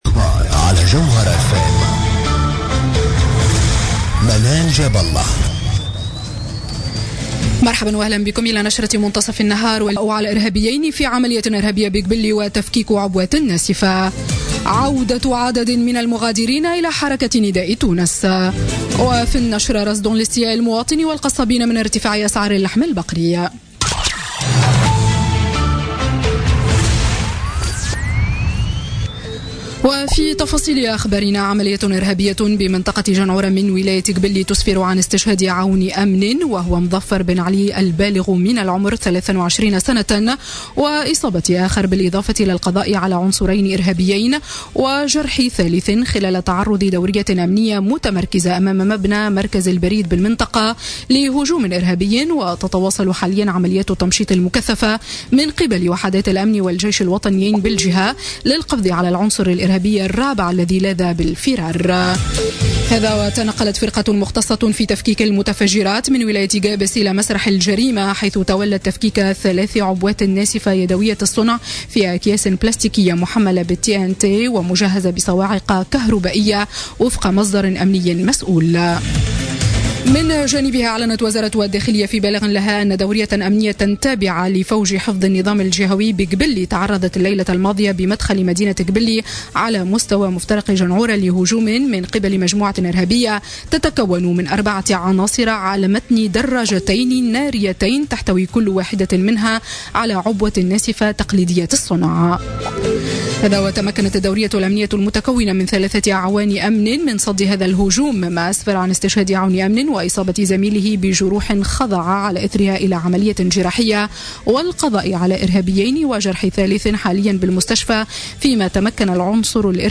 نشرة أخبار منتصف النهار ليوم الأحد 12 مارس 2017